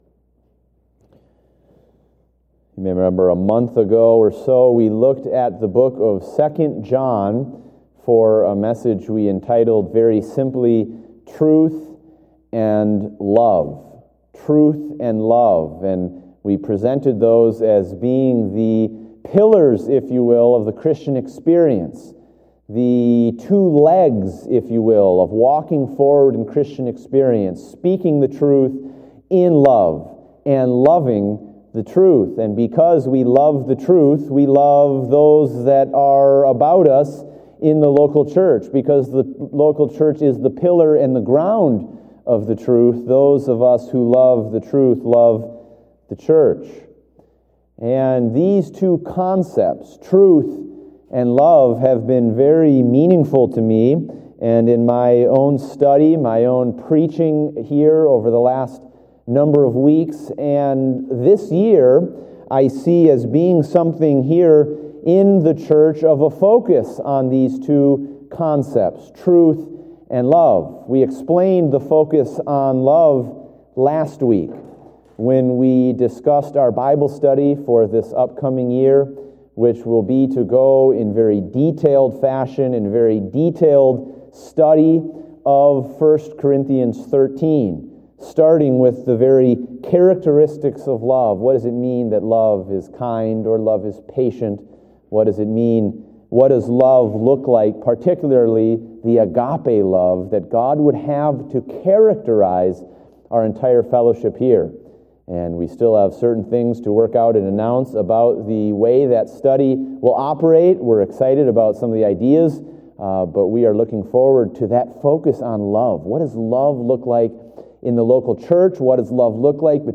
Date: January 10, 2016 (Evening Service)